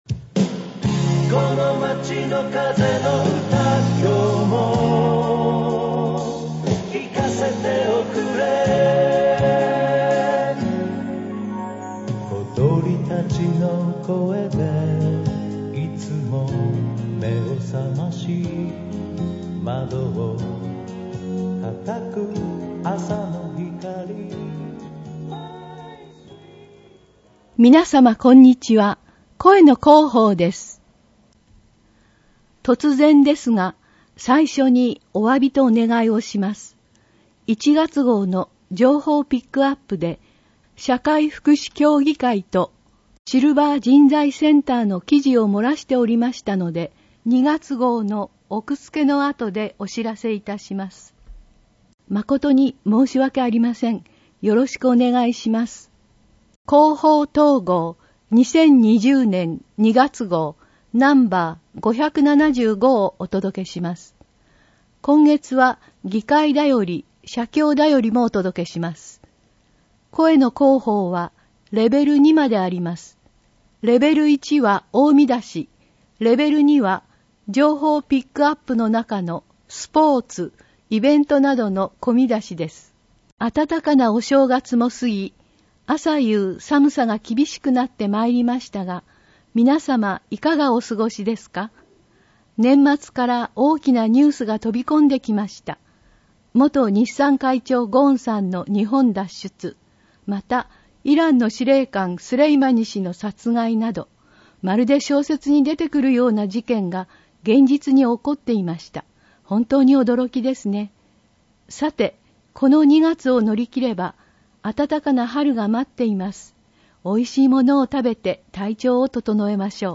広報とうごう音訳版（2020年2月号）